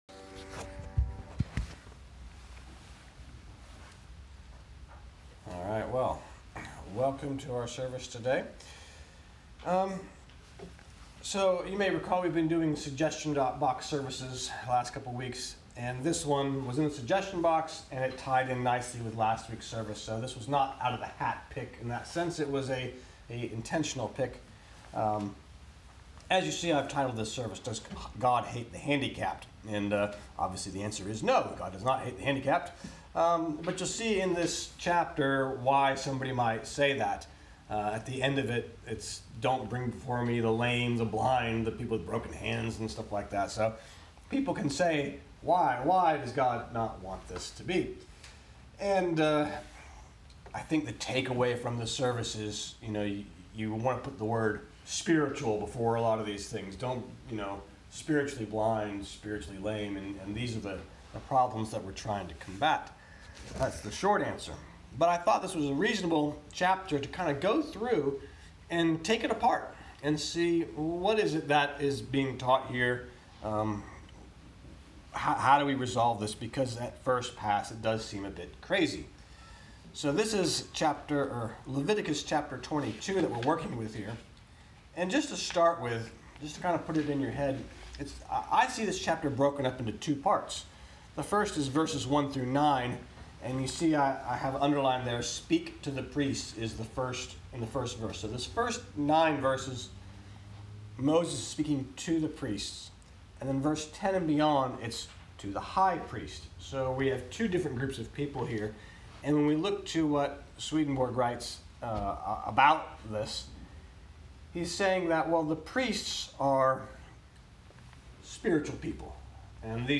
Audio Messages | Sermon | mp3
Listen to a recorded worship service